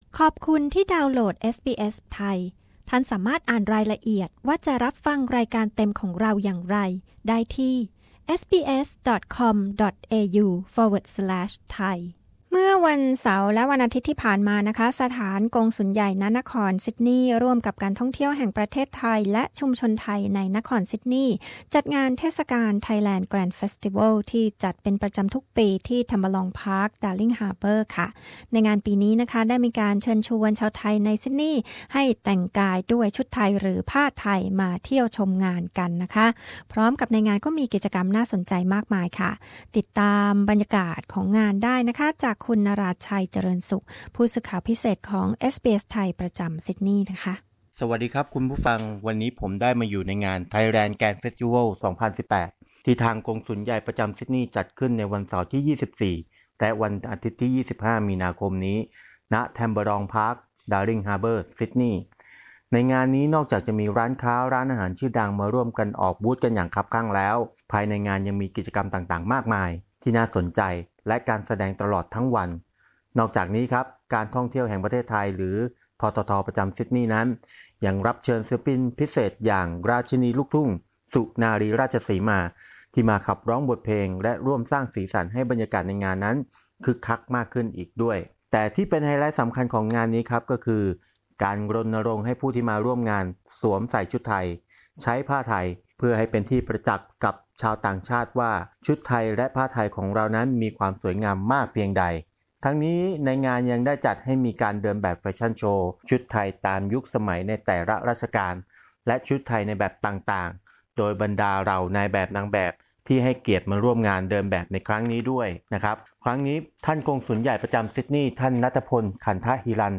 เอสบีเอส ไทย พูดคุยกับประชาชนไทย ที่ไปร่วมงานไทยแลนด์ แกรนด์ เฟสติวัล 2018 ว่าพวกเขาประทับใจอย่างไรกับงานในปีนี้